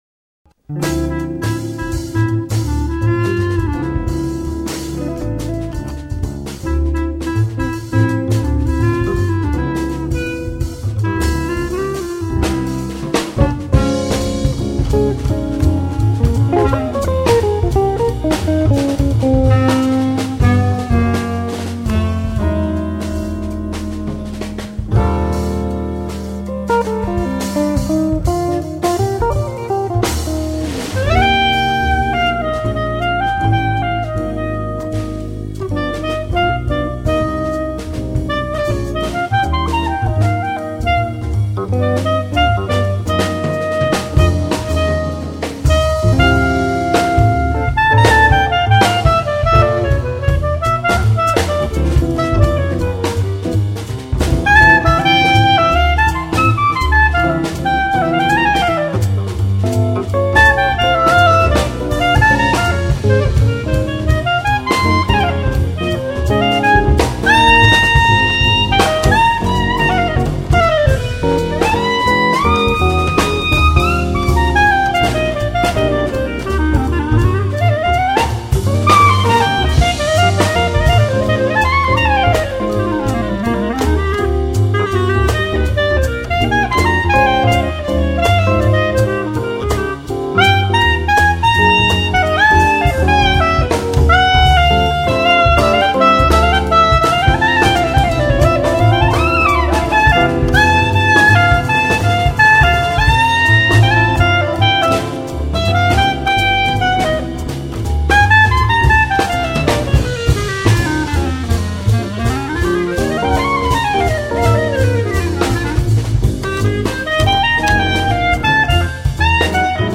1998 Jazz